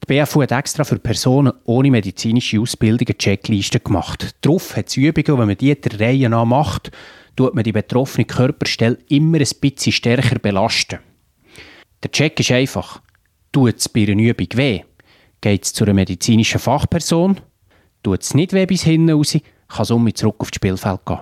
O-Ton zum Download